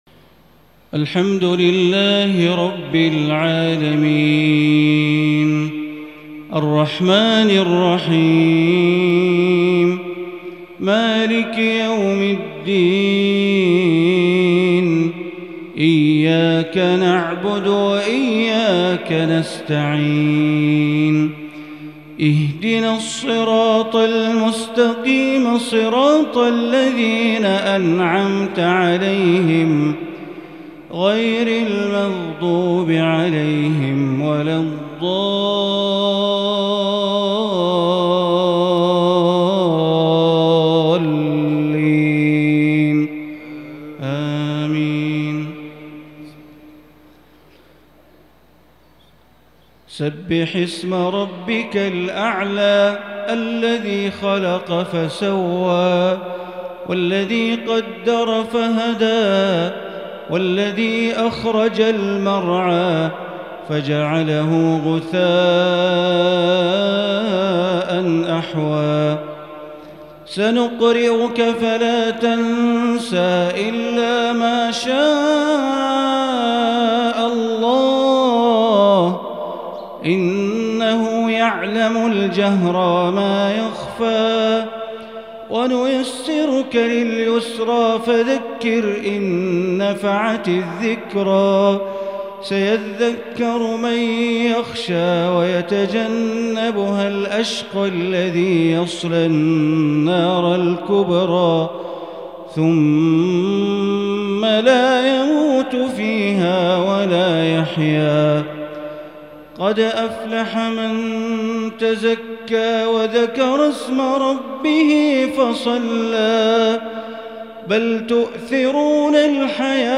صلاة الجمعة 5-4-1442 تلاوة من سورة الأعلى والغاشية > 1442 هـ > الفروض - تلاوات بندر بليلة